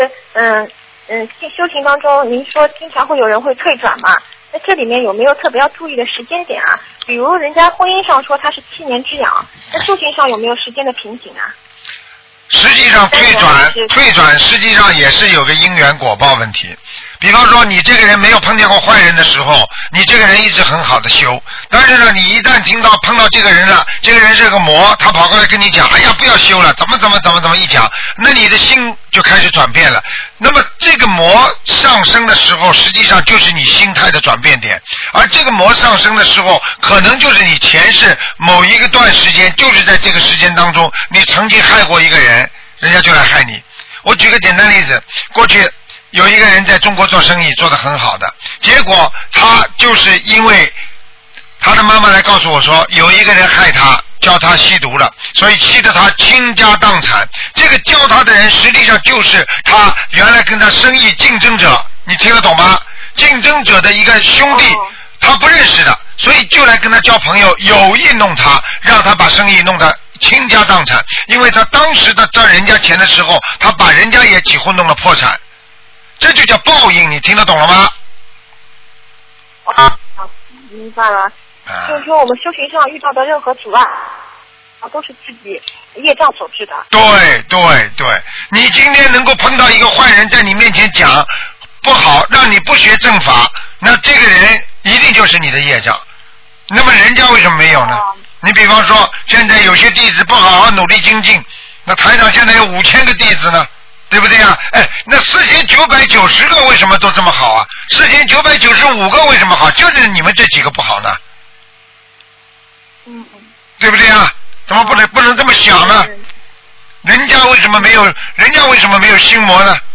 Tanya Jawab